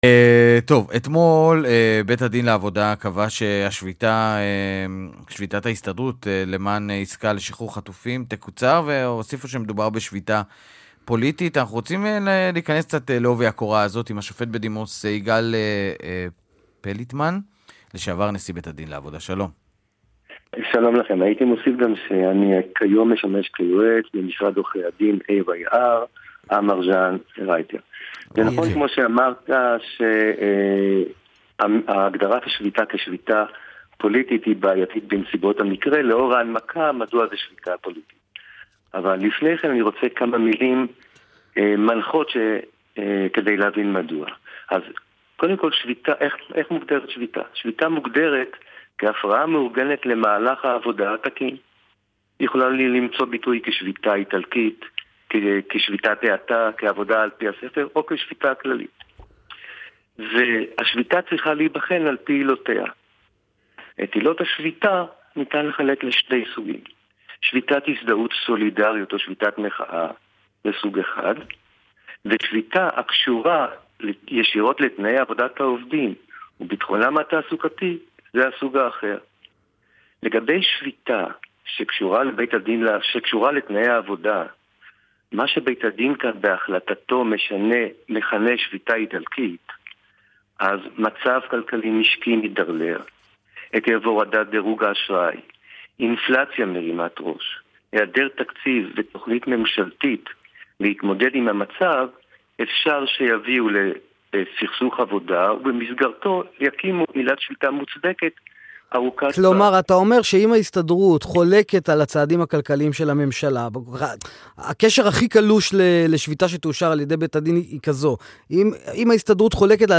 ריאיון עם נשיא ביה״ד הארצי לעבודה לשעבר, השופט בדימוס יגאל פליטמן, בנושא מהות שביתת ההזדהות ופסיקת ביה״ד להפסקתה